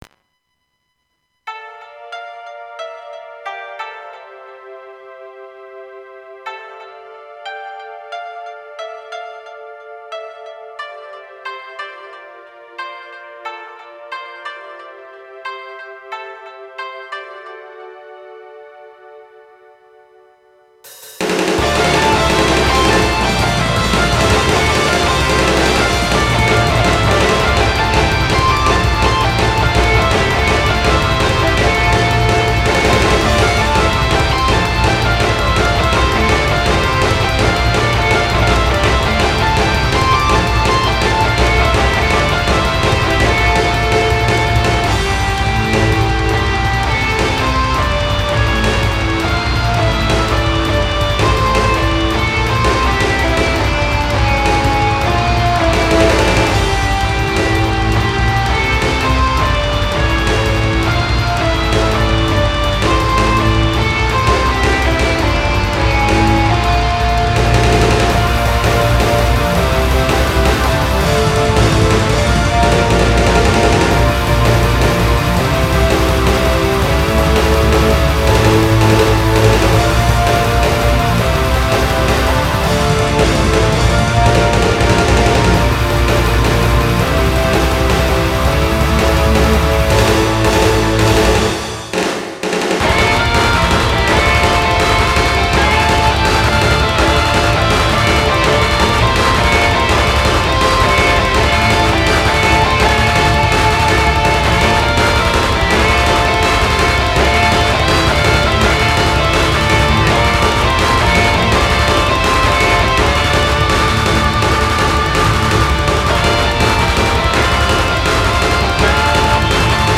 อินโทรได้มันส์โดนใจมากครับ